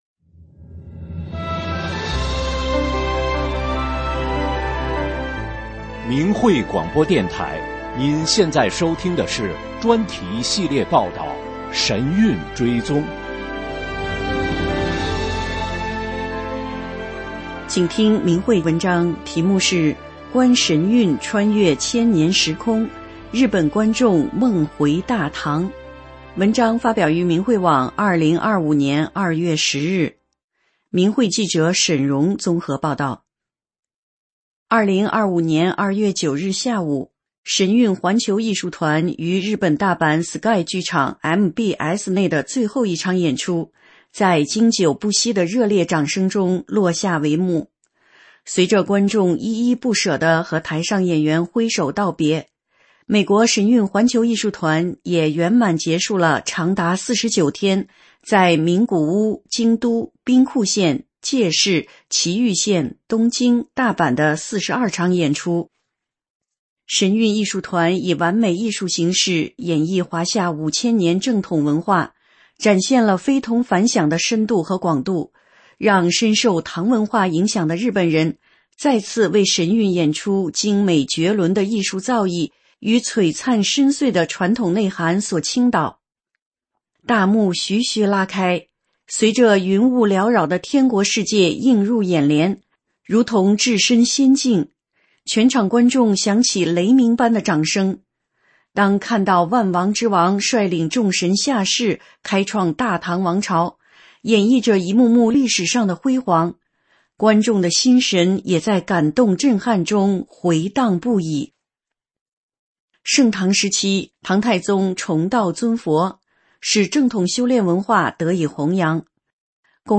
真相广播稿 观神韵穿越千年时空 日本观众梦回大唐 发表日期： 2025年2月16日 节目长度： 14分49秒 在线收听 下载 3,991 KB 3,472 KB 下载方法 ：按鼠标器右键，在弹出菜单中选择“目标文件保存为…”（Save Target A s…）